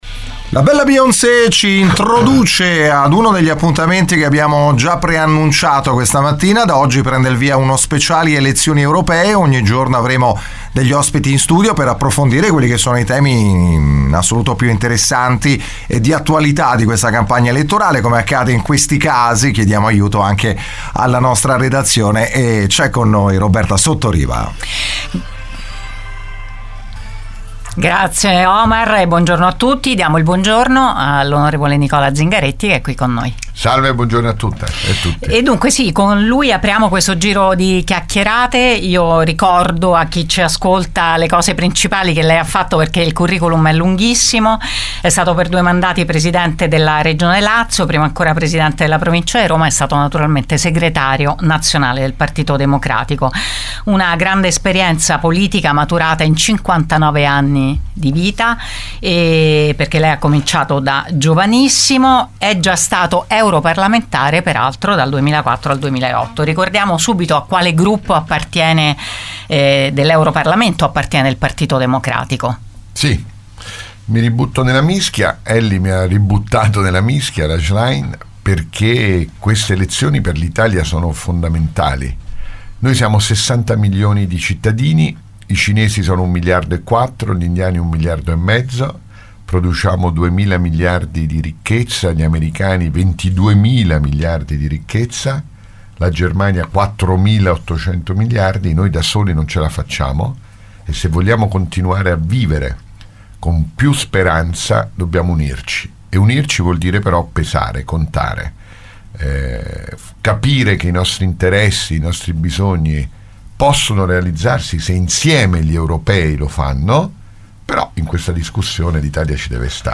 L’onorevole Nicola Zingaretti, candidato del Pd alle prossime elezioni europee, impegnato oggi in un tour in provincia di Latina, ha sintetizzato così su Radio Immagine la sua idea di Unione Europea.